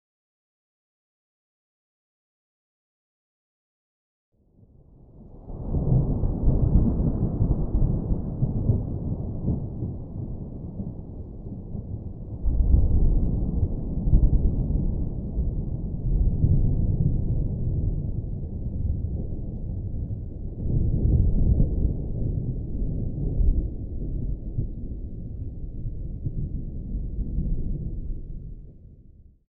Atmospherics / gamedata / sounds / nature / very_far4.ogg